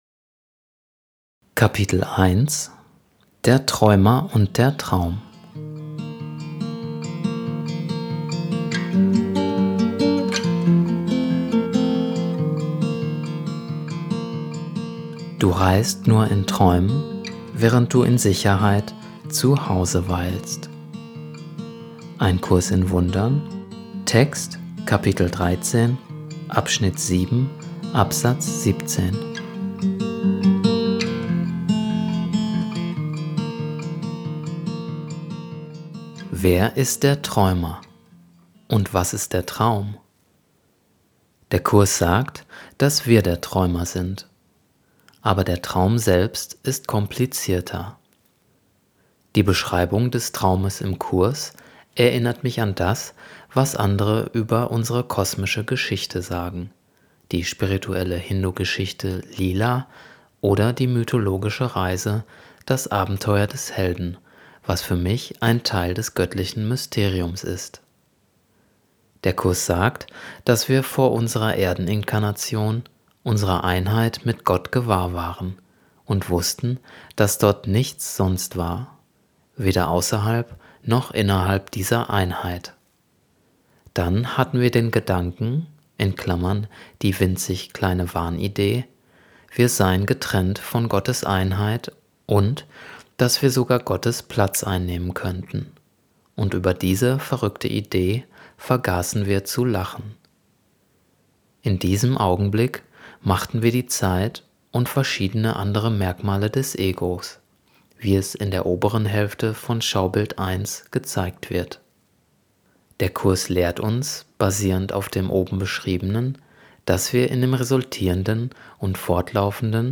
1 Lehrer Gottes Hörspiel Kapitel 1.mp3